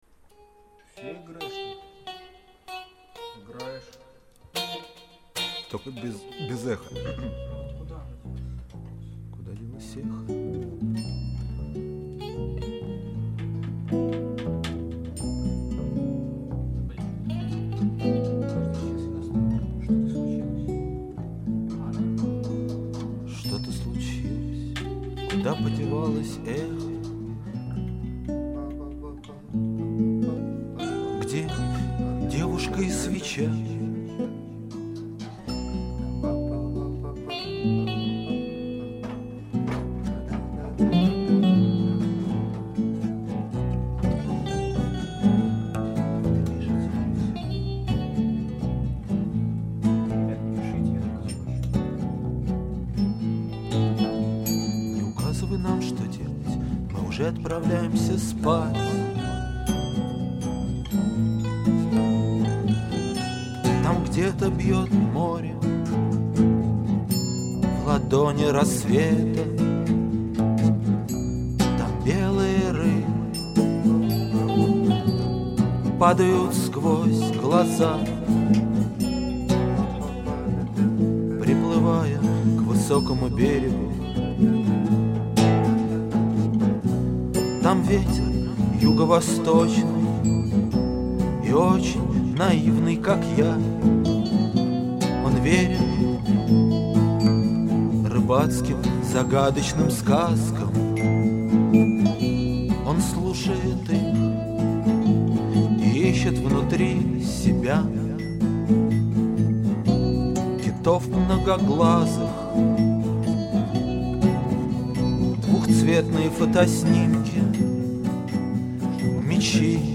Буквой (и) отмечены импровизации.
голос
бас-гитара
ударные    Обложка